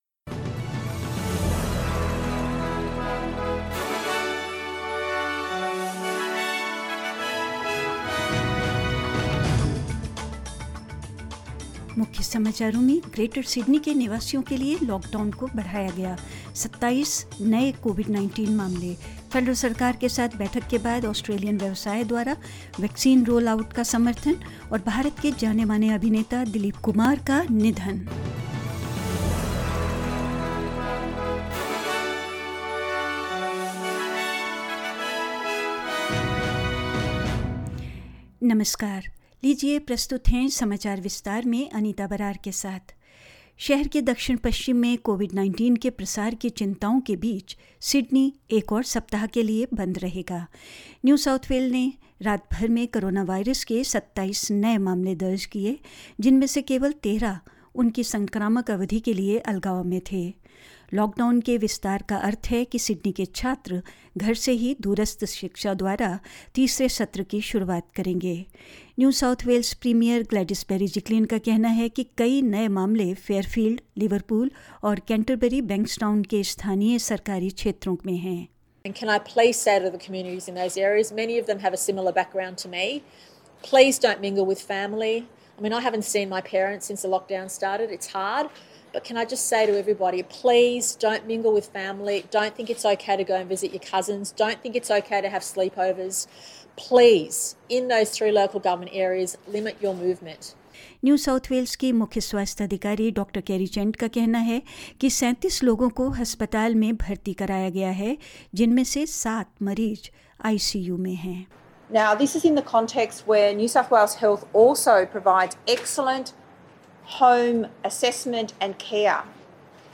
In this latest SBS Hindi News bulletin of Australia and India: NSW reports 27 new COVID-19 cases; Australian businesses to support the vaccine rollout after meeting with the Federal Government; Indian actor Dilip Kumar passes away at 98 and more news